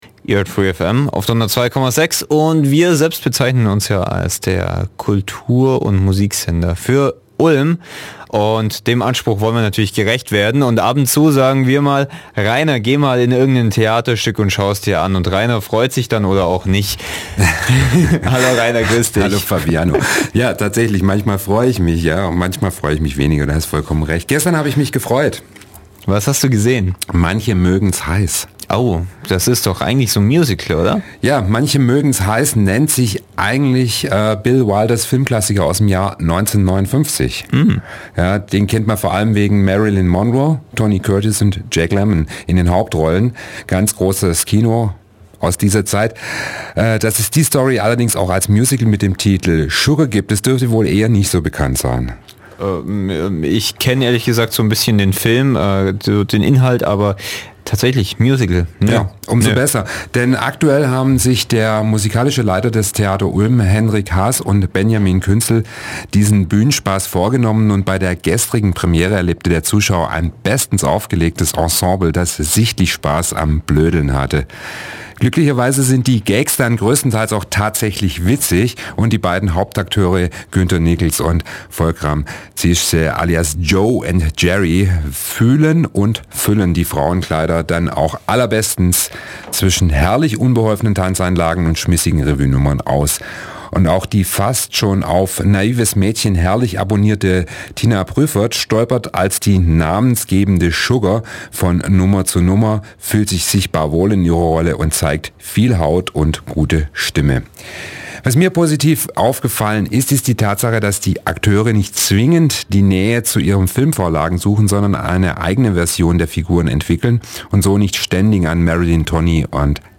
"Sugar" Premieren-Kritik 23.3.12